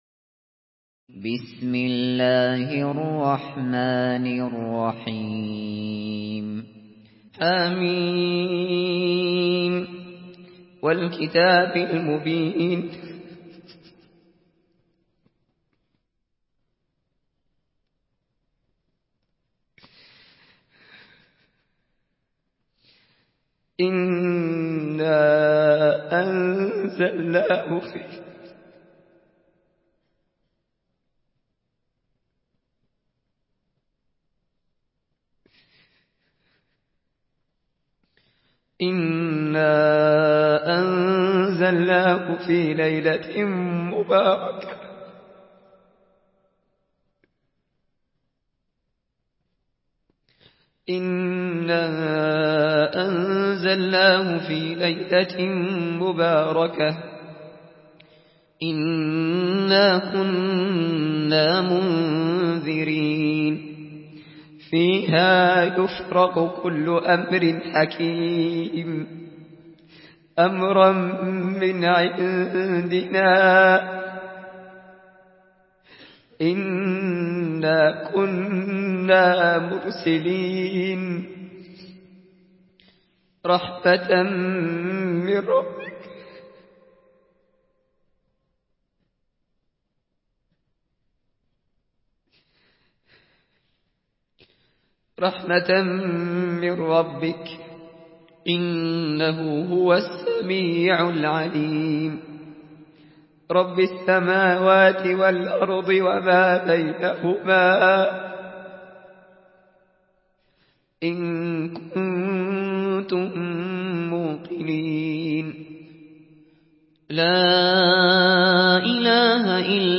Surah Ad-Dukhan MP3 by Abu Bakr Al Shatri in Hafs An Asim narration.